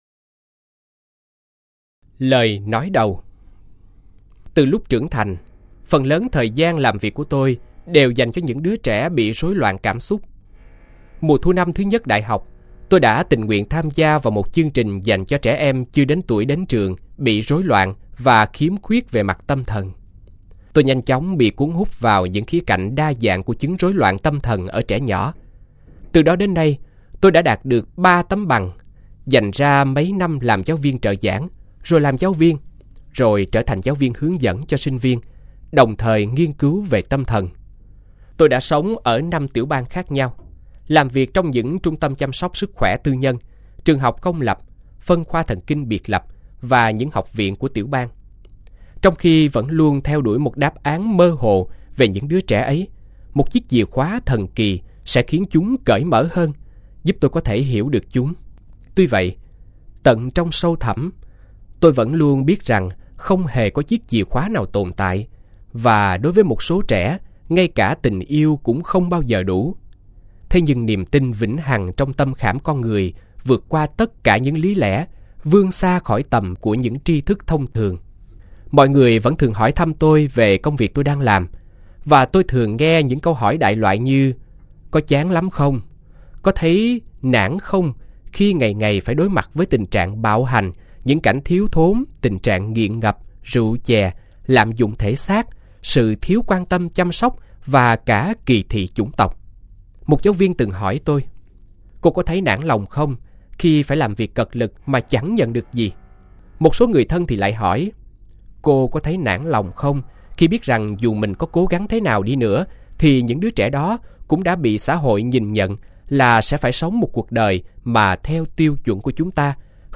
Sách nói | Đêm tối và ánh sáng